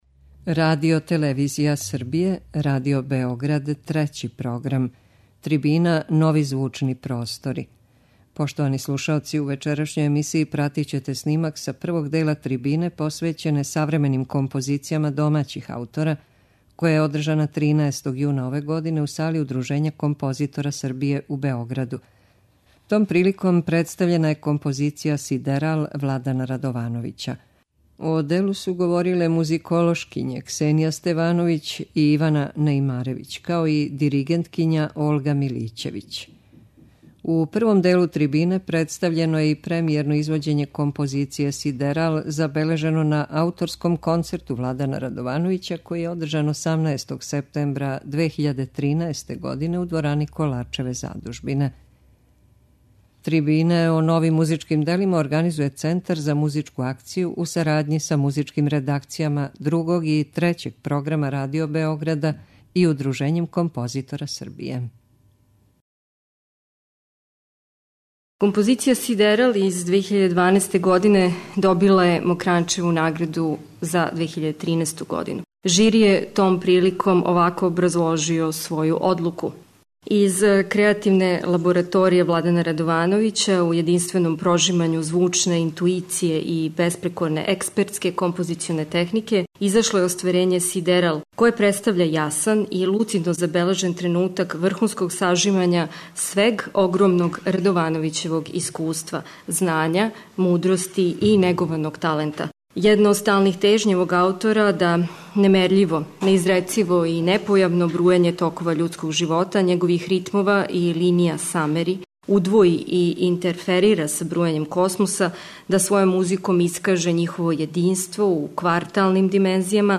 Слушаћете снимак са првог дела трибине посвећене савременим композицијама домаћих аутора, која је одржана 13. јуна ове године у Сали Удружења композитора Србије у Београду. Том приликом представљена је композиција 'Сидерал' Владана Радовановића.